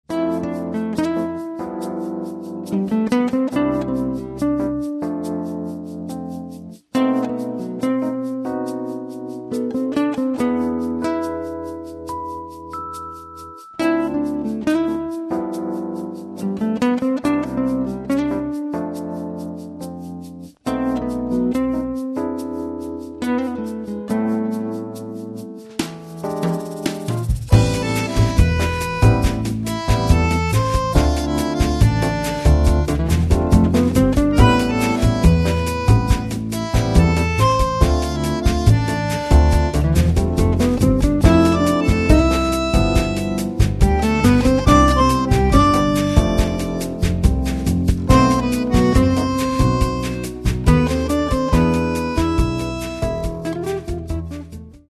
Каталог -> Джаз и около -> Этно-джаз и фольк
bass, keyboards, vocals, percussion, programming
guitars
flute
drums, percussion
trumpet, flugelhorn
trombone
accordion